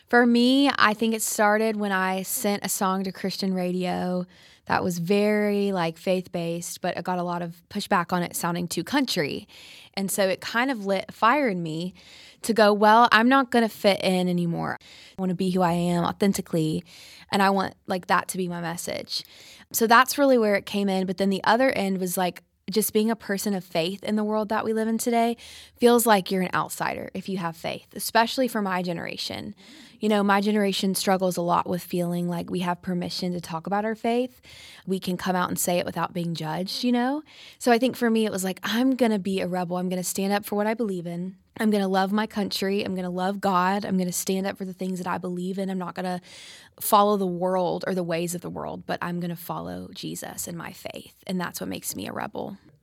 Anne Wilson's new album is titled REBEL, and she explains just what makes her a REBEL.